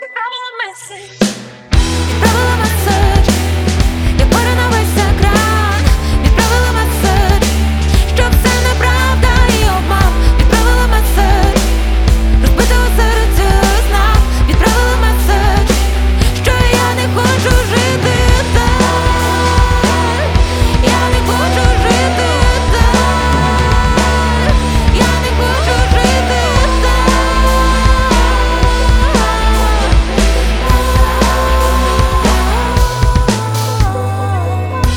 Pop Rock Pop
Жанр: Поп музыка / Рок / Украинские